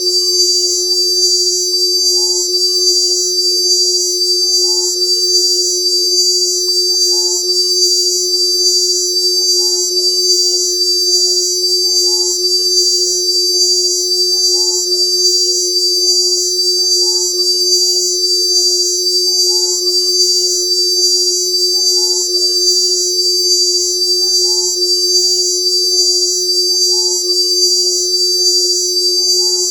Bulova_accutron_summton.ogg